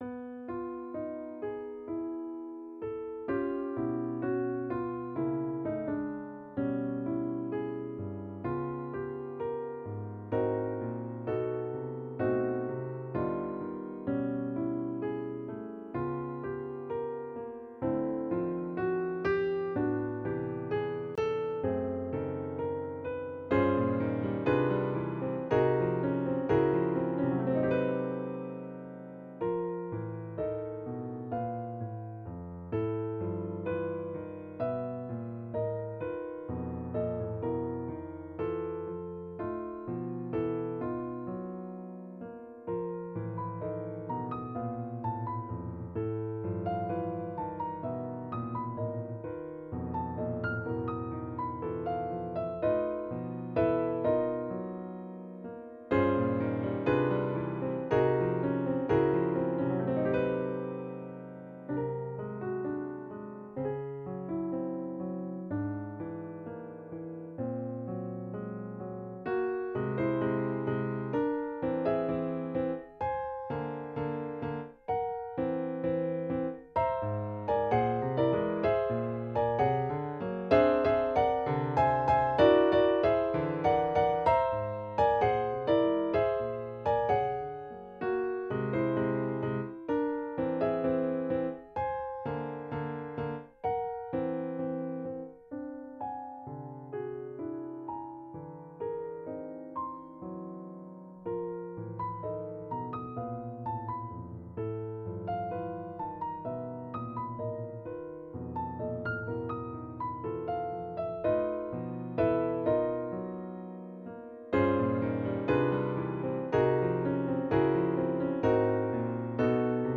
Killed by Numbers - Piano Music, Solo Keyboard